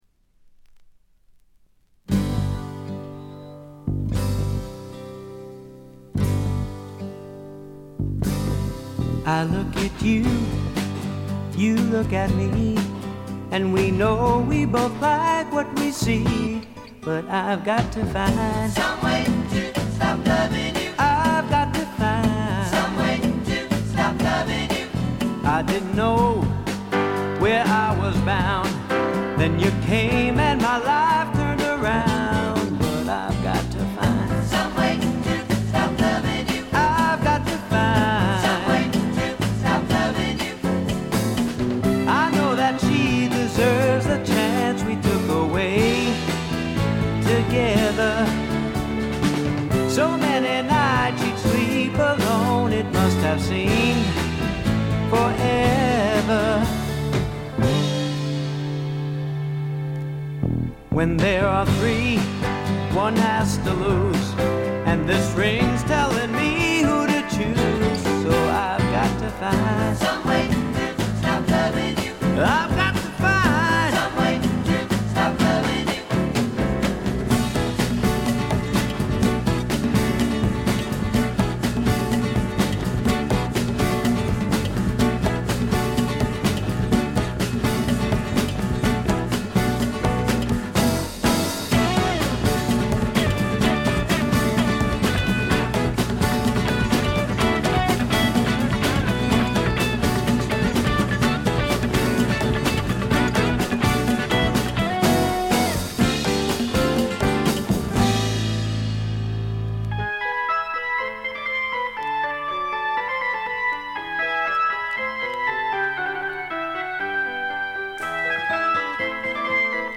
軽微なチリプチ少々。
定番的に聴きつがれてきたプリAOR、ピアノ系シンガーソングライター作品の大名盤です。
試聴曲は現品からの取り込み音源です。
Keyboards, Percussion, Vocals